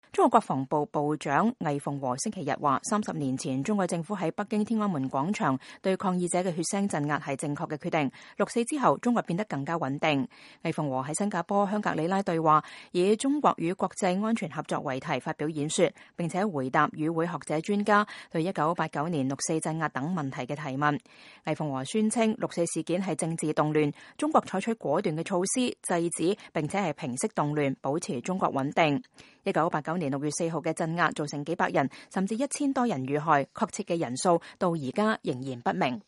中國國防部長魏鳳和2019年6月2日在香格里拉對話會上發言（路透社）
魏鳳和在新加坡“香格里拉對話”以“中國與國際安全合作”為題發表演說，並回答與會學者專家對1989年六四鎮壓等問題提問。